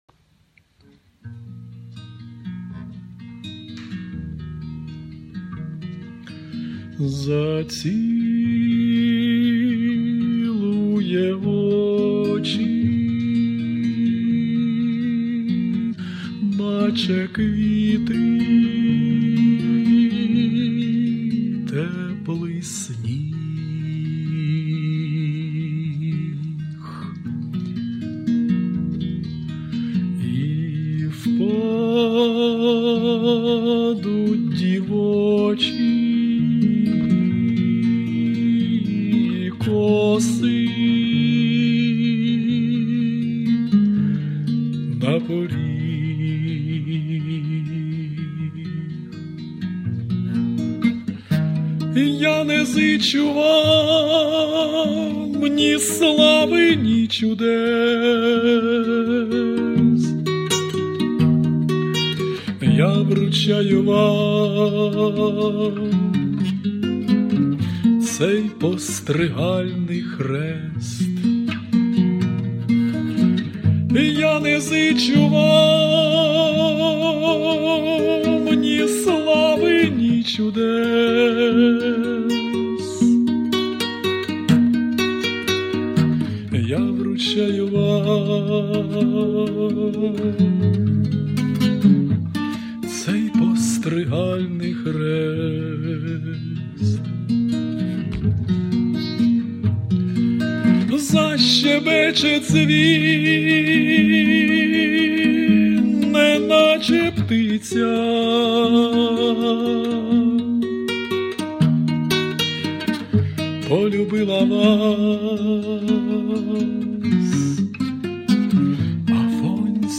Авторська пісня
Рубрика: Поезія, Духовна поезія
Аж серце завмерло... 16 hi Дуже гарний тембр голосу, співаєте з почуттям.